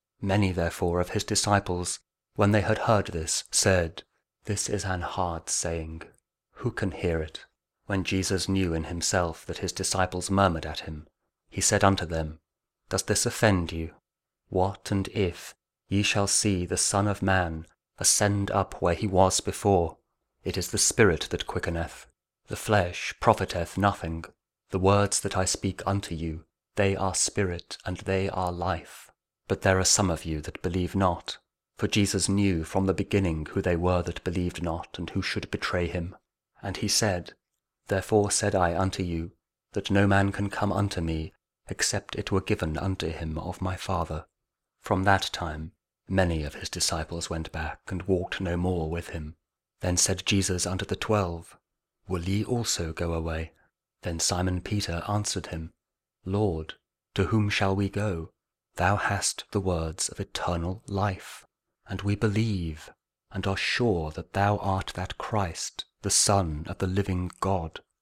John 6: 60-69 | King James Audio Bible | Daily Verses